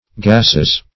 Gas \Gas\ (g[a^]s), n.; pl. Gases (g[a^]s"[e^]z).